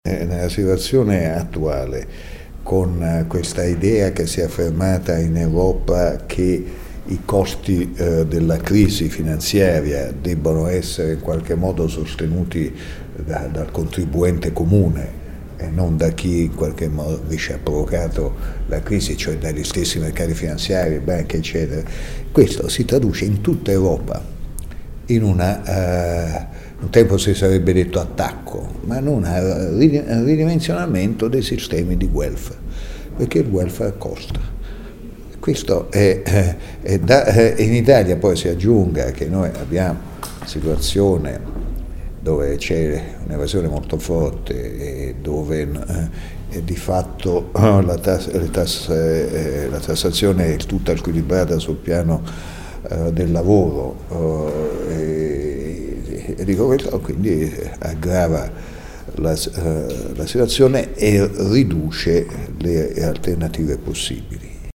10 dic. – A margine di un seminario organizzato dalla Cgil questa mattina sulla riforma del fisco, l’ex ministro Vincenzo Visco è intervenuto sulle politiche adottate dai governi europei per uscire dalla crisi: “In Europa si è affermata l’idea che i costi della crisi finanziaria debbano essere sostenuti dal contribuente comune e non dagli stessi mercati finanziari, in italia la situazione è aggravata dal fatto che la tassazione è tutta squilibrata sul piano del lavoro in un contesto di evasione molto forte”, ha detto Visco.